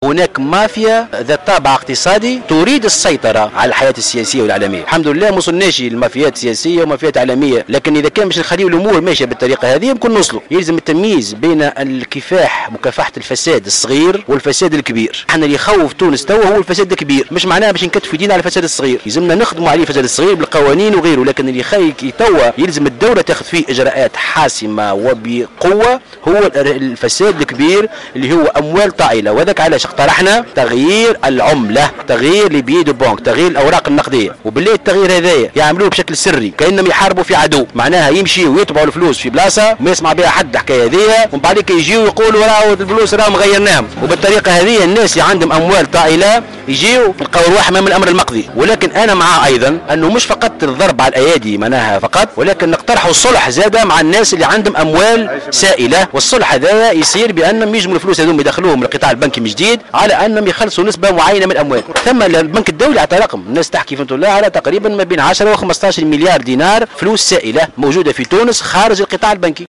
وأقر مرزوق في تصريحات لمراسل "الجوهرة أف أم" اليوم الأحد بوجود الفساد في البلاد من خلال مافيا ذات طابع اقتصادي على حد تعبيره تريد السيطرة على الحياة السياسية والاعلامية في البلاد.